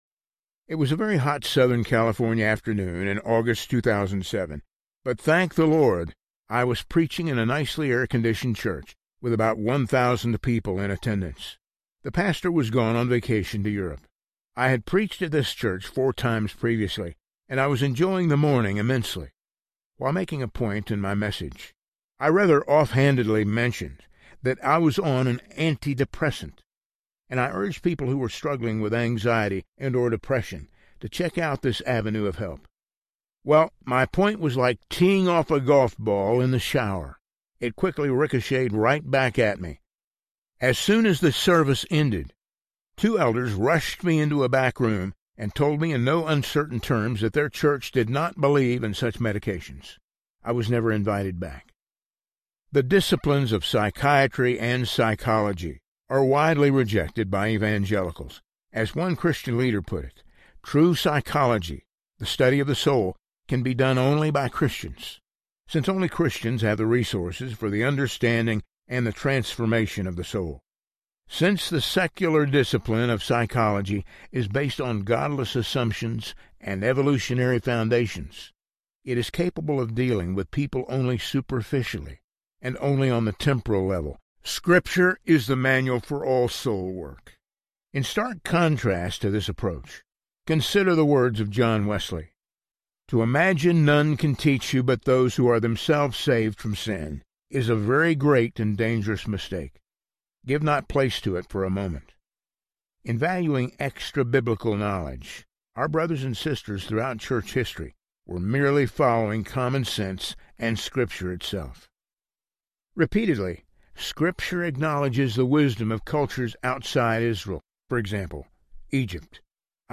Finding Quiet Audiobook
Narrator
4.5 Hrs – Unabridged